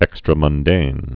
(ĕkstrə-mŭn-dān, -mŭndān)